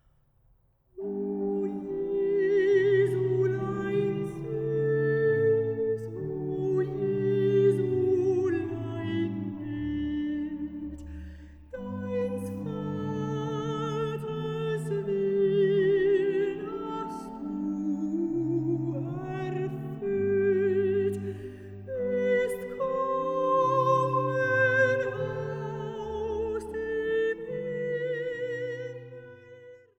Weihnachtliche Orgelmusik
Orgel